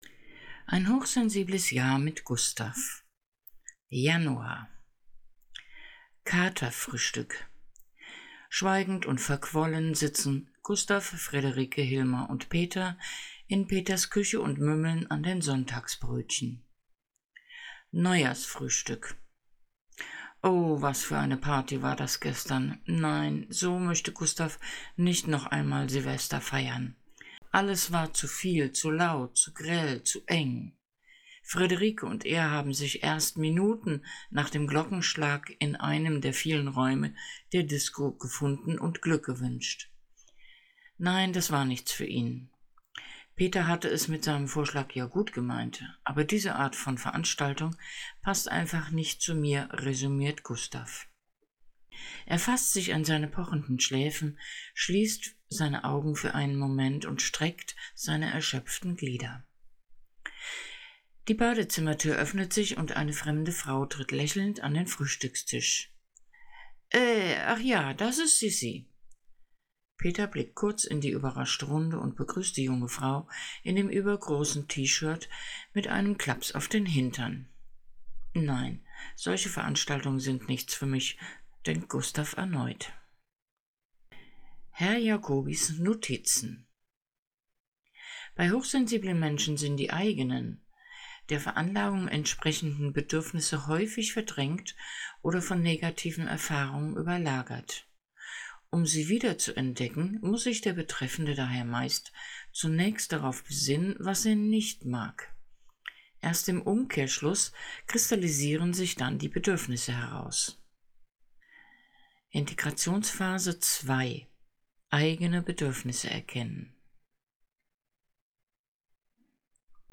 Hörprobe "Ein hochsensibles Jahr mit Gustav" -Erste Episoden